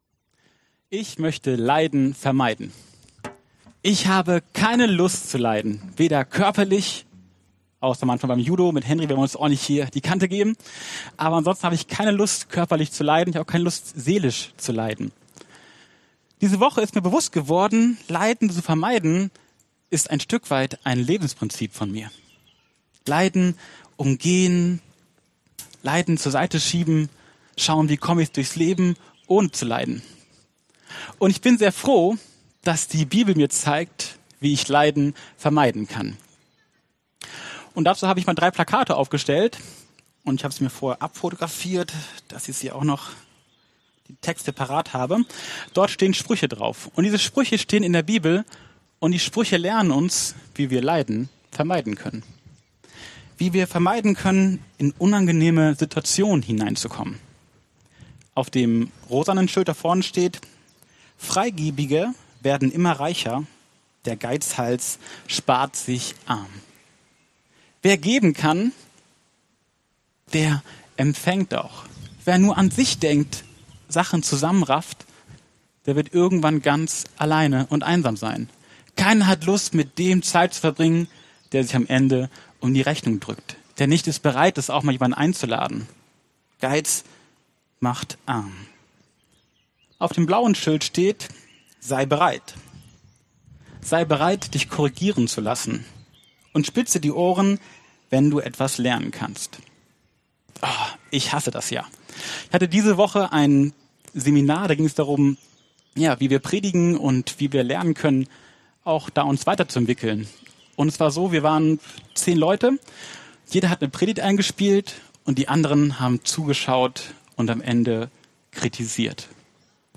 Diese Predigt gibt es auch mit Video bei YouTube.